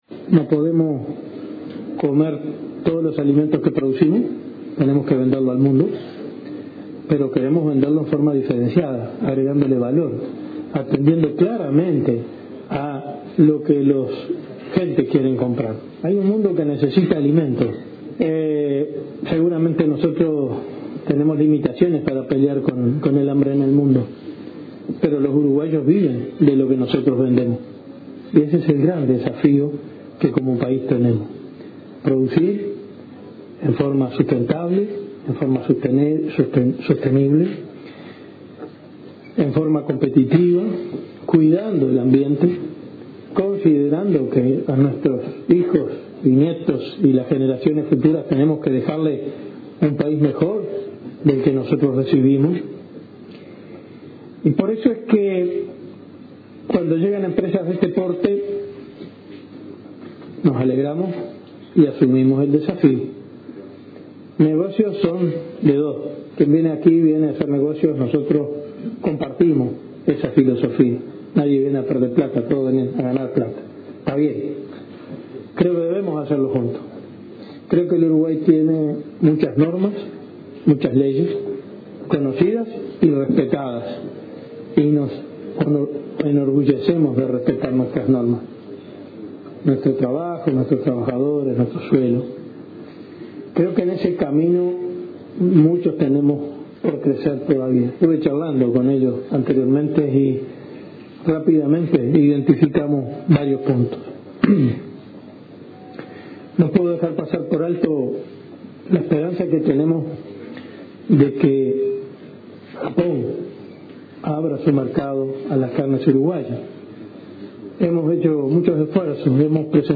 “Como gobierno, tenemos la responsabilidad trabajar juntos”, sostuvo el ministro interino de Ganadería, Enzo Benech, durante la presentación de la empresa NH Foods en Durazno.